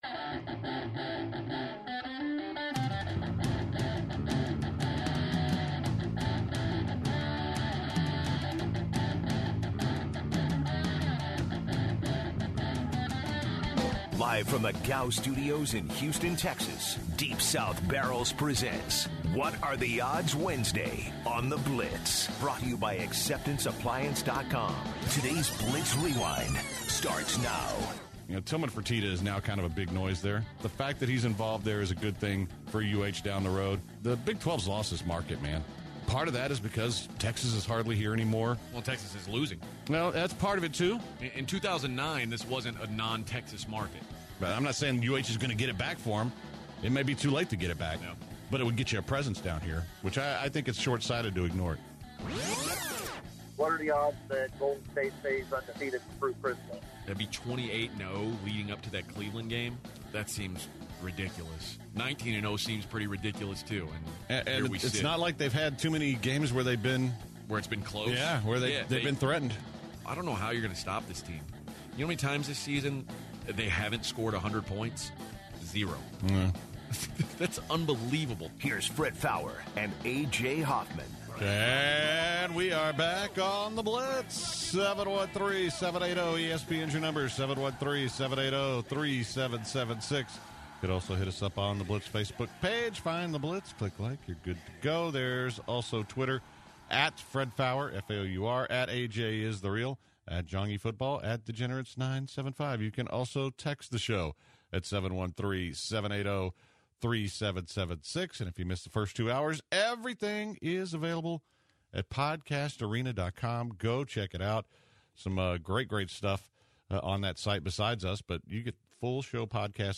The third hour was filled with What Are the Odds callers for the Moron game that was played this hour. The guys debated what the best Christmas movie ever made is.